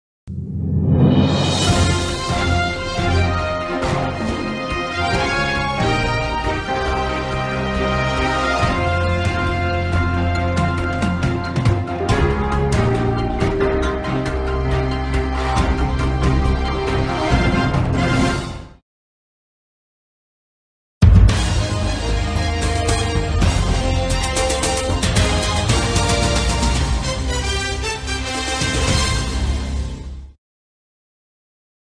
دانلود آهنگ مقدمه خبر از افکت صوتی اشیاء
جلوه های صوتی